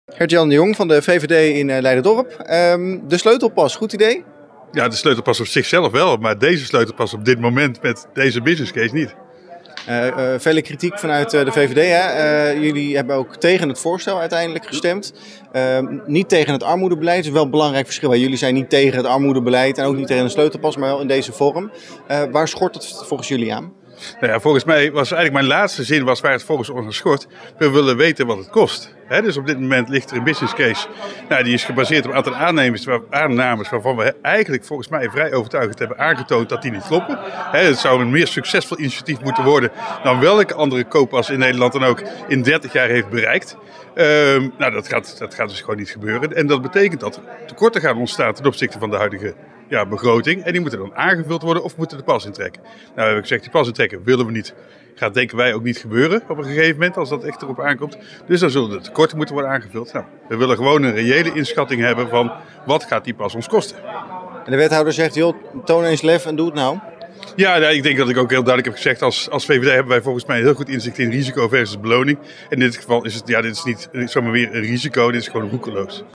VVD-raadslid Gert-Jan de Jong heeft kritiek op de businesscase van de Sleutelpas.